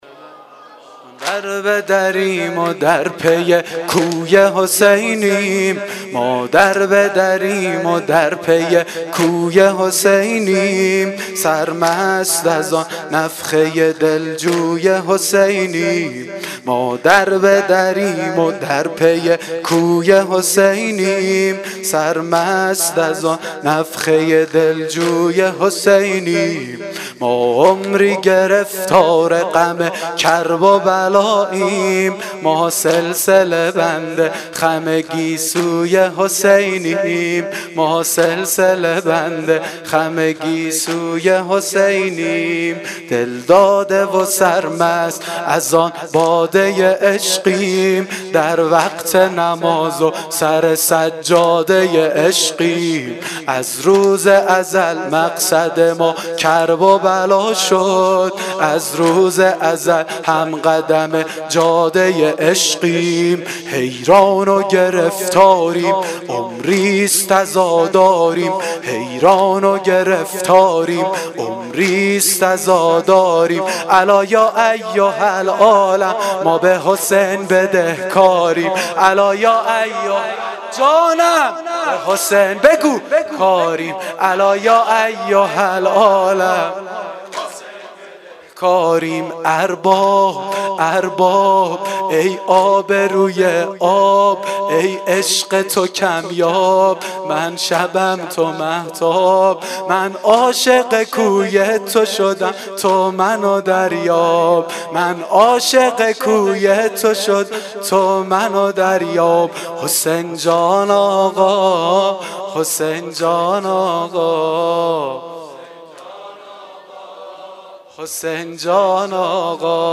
واحد شب هشتم محرم